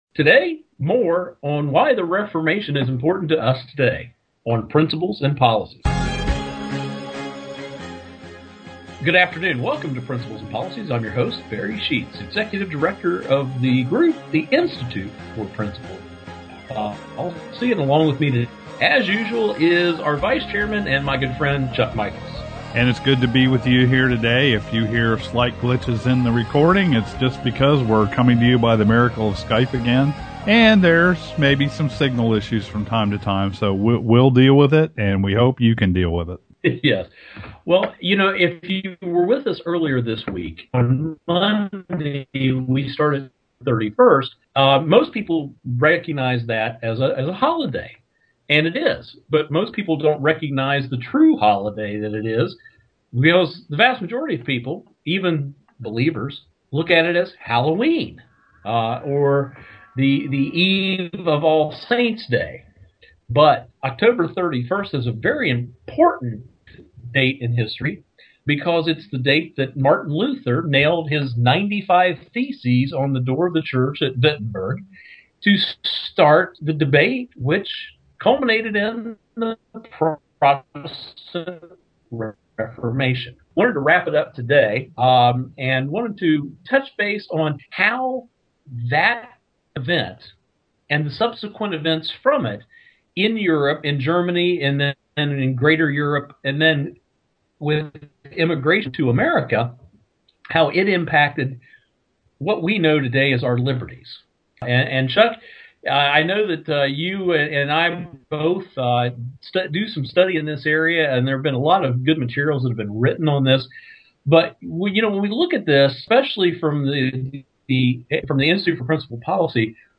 Our Principles and Policies radio show for Wednesday November 2, 2011.